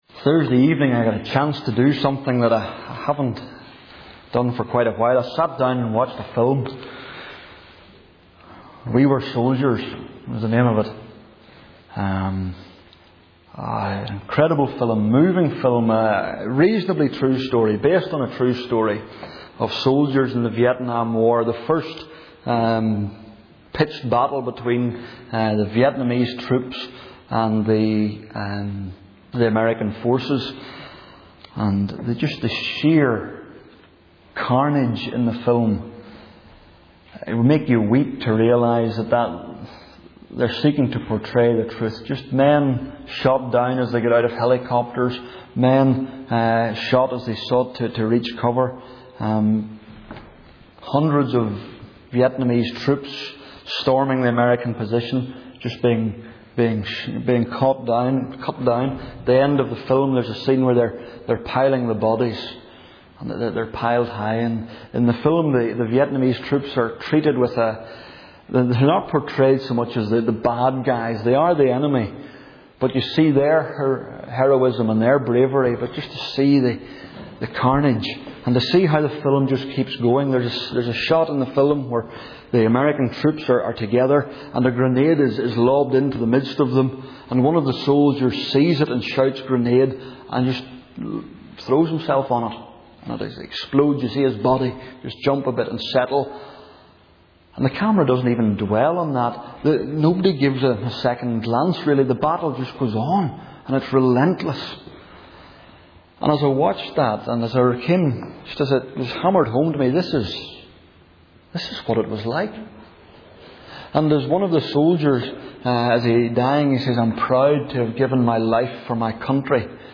Archives for August 2007 | Single Sermons | new life fellowship